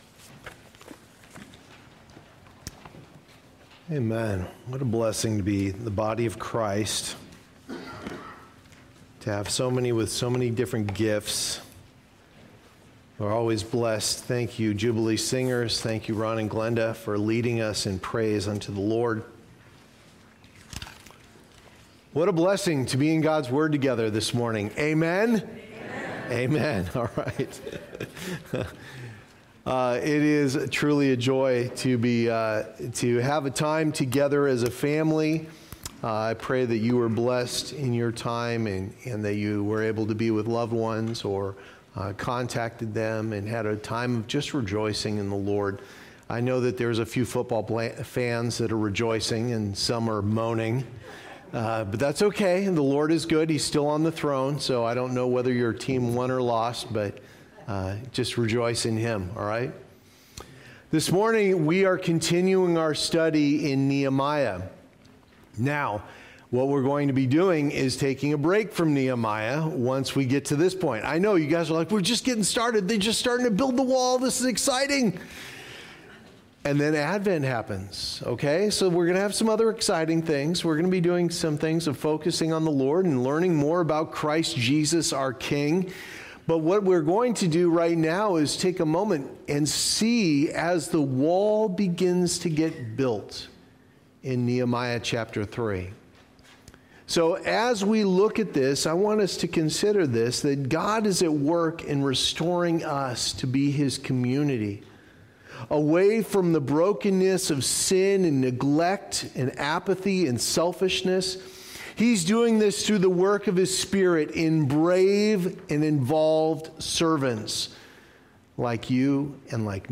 Sunday Morning Service Download Files Notes Previous Next